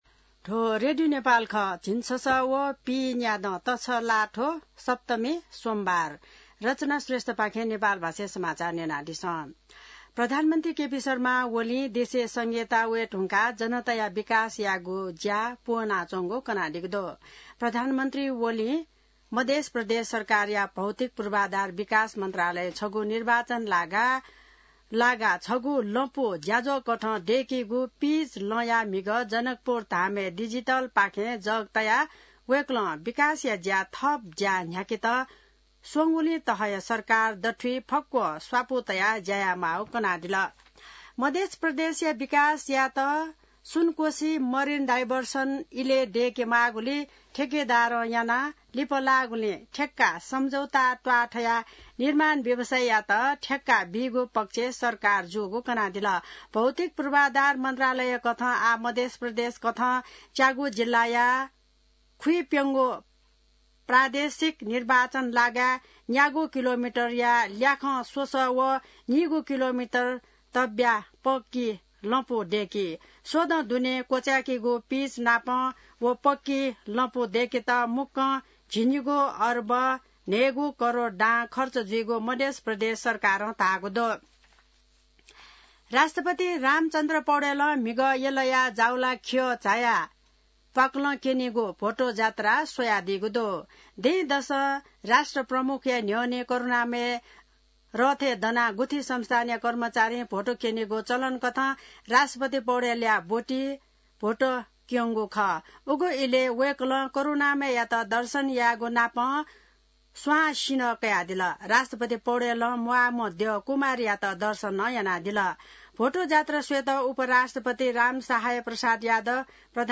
नेपाल भाषामा समाचार : १९ जेठ , २०८२